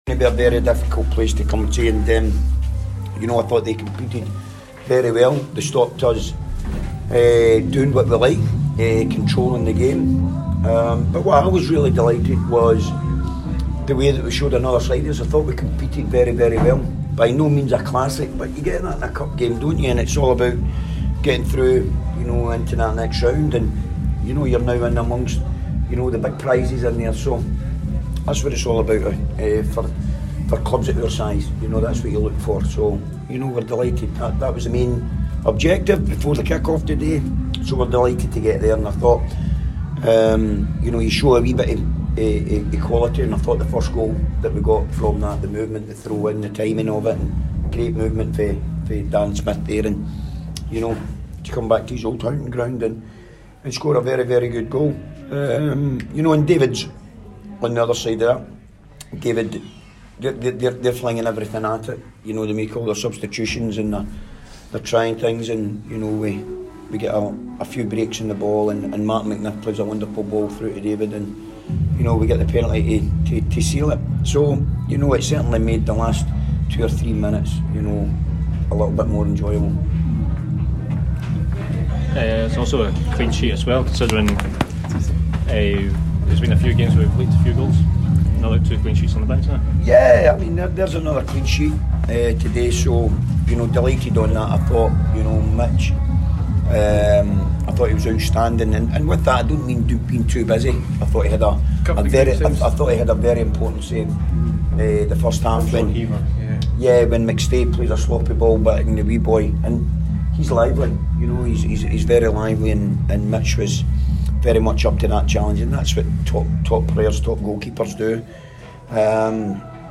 press conference after the William Hill Scottish Cup match.